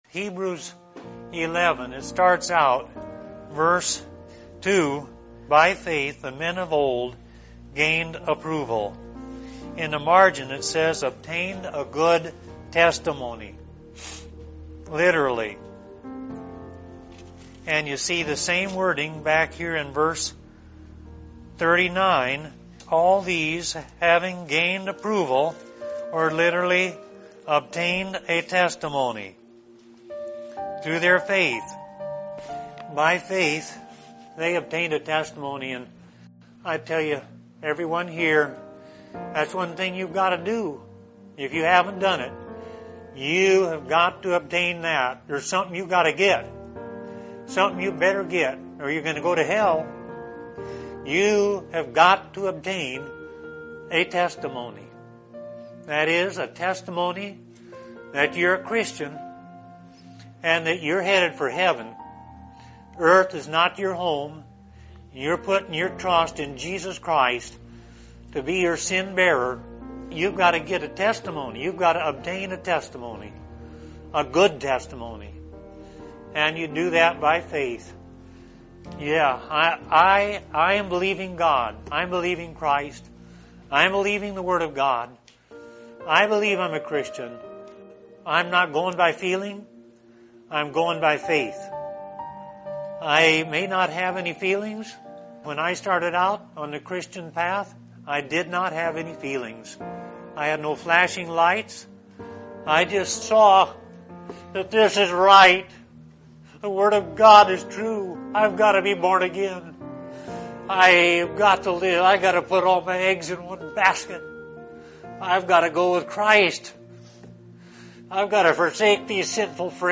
From the full sermon, All These Died in Faith, Confessing